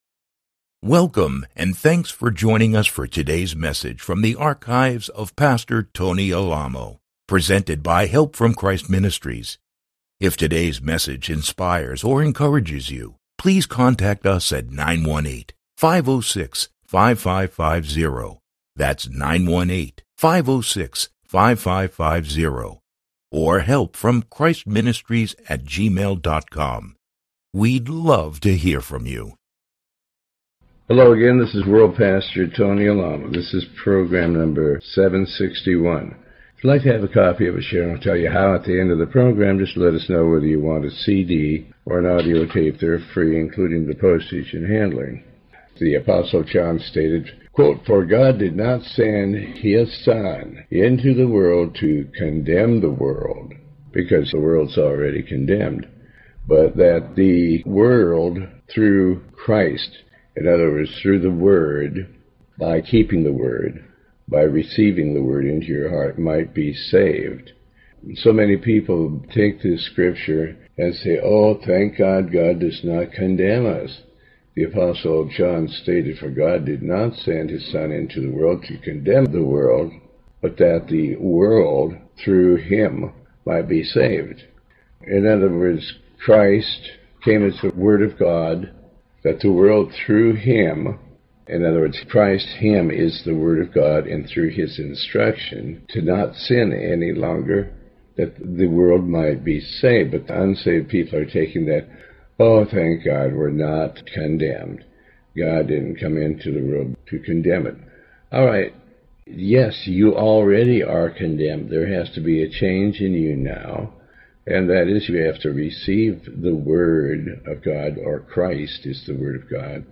Pastor Alamo reads and comments on the Book of Revelation chapter 4. This program is part of a series covering the entire Book of Revelation.